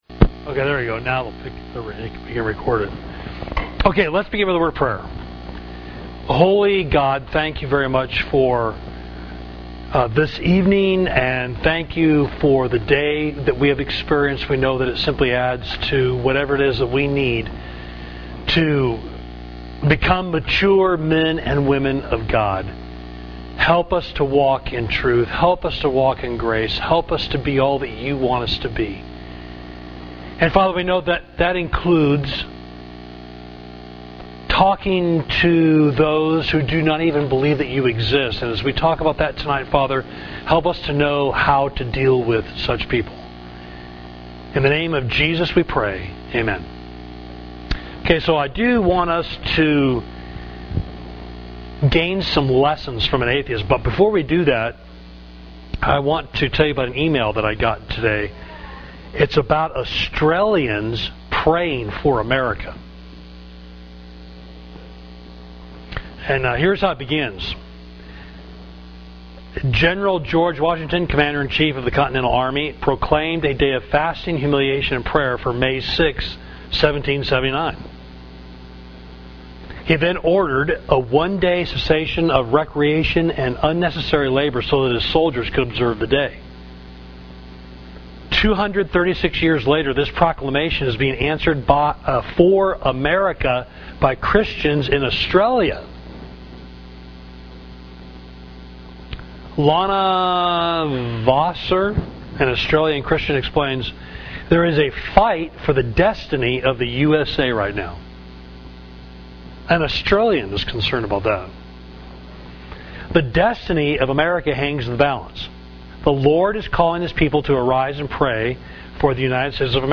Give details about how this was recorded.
Sorry for the poor sound quality, and that the recording ends early.